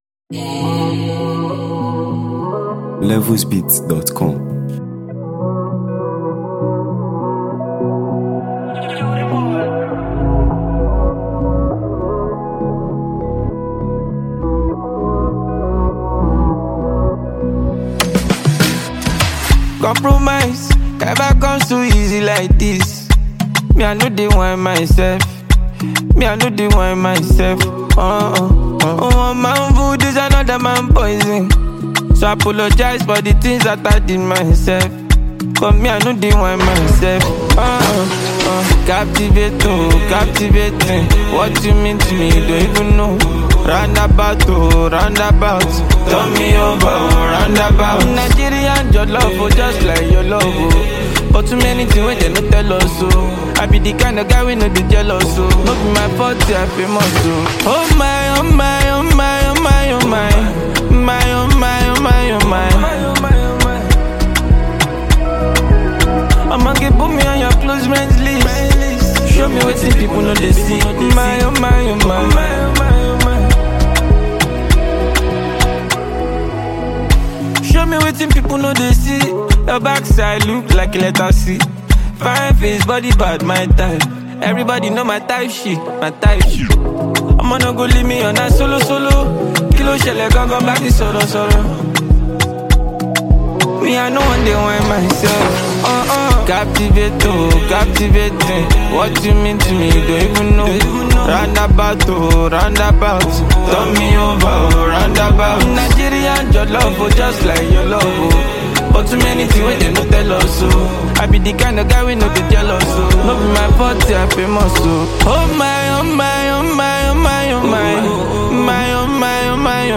Multi-talented Nigerian hitmaker and versatile songwriter
With its vibrant groove and addictive vibe